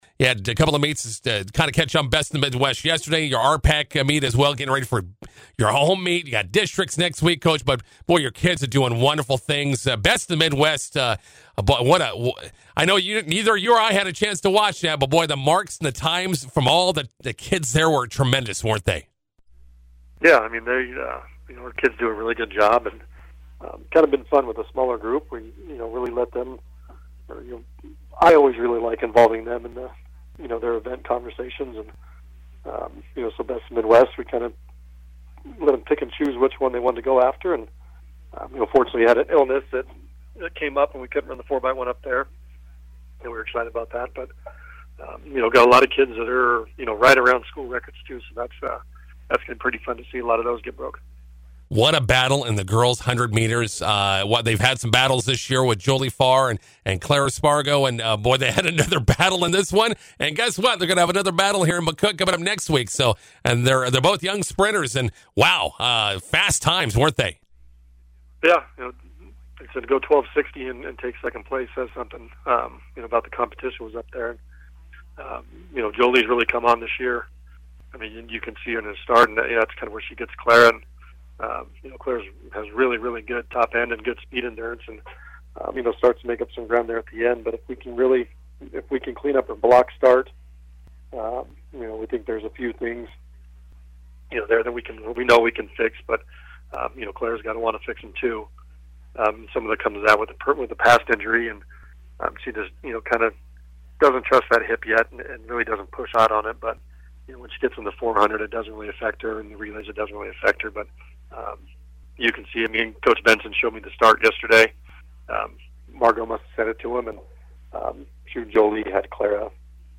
INTERVIEW: Dundy County-Stratton Track and Field hosting their own invite today, preparing for district meet in McCook next week.